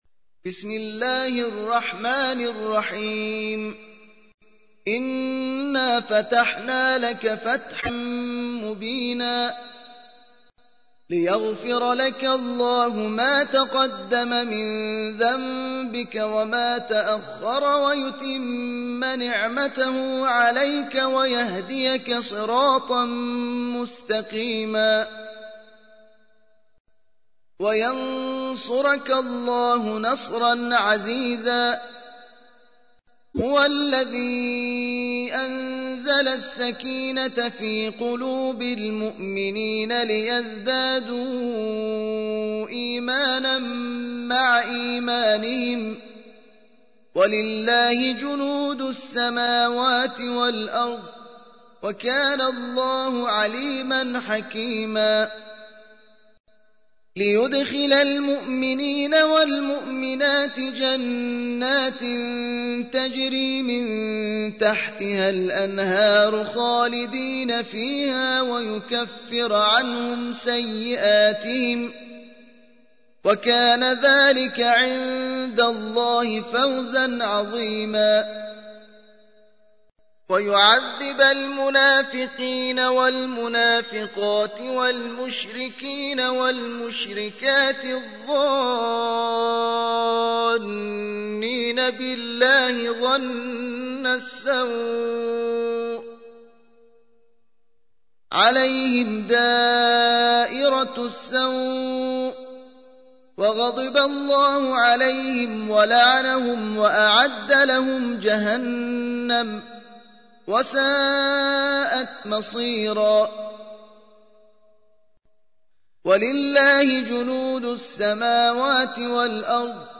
48. سورة الفتح / القارئ